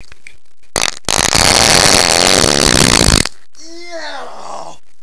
When I eat this powerful drug, he's sure to thunder through my intestines like a lightning bolt the sky.
Click Yonder Flame to hear one of such farts. I advise to turn the treble off, the bass up, and to put the volume on max, oh, and don't forget to glue your ear to the speaker, you're in for a ride!
ffart.wav